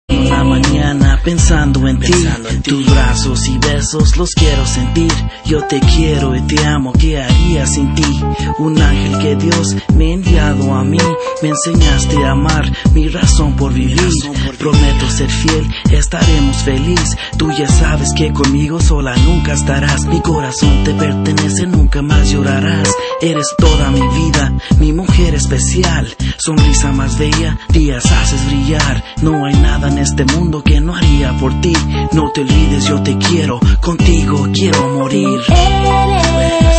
• Latin Ringtones